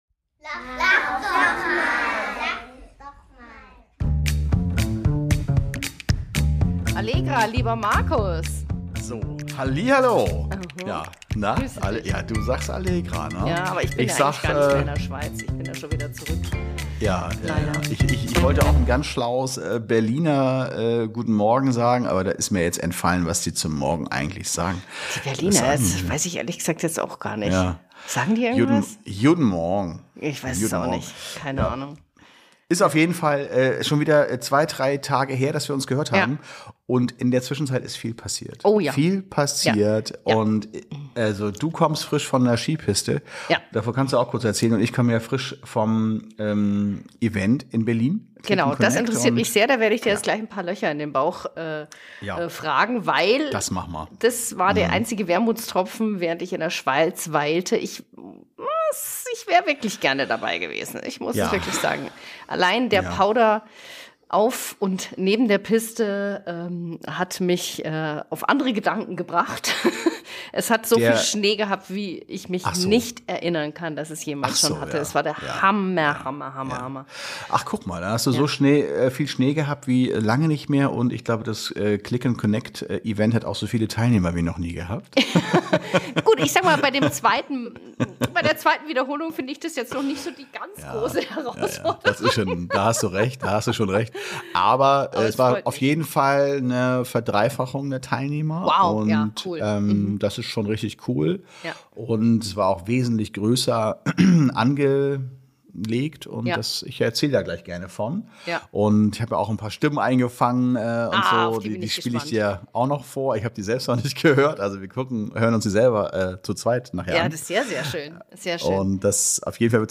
Zum Schluss wird’s besonders authentisch: Mehrere Teilnehmer kommen LIVE zu Wort und teilen ihre persönlichen Highlights.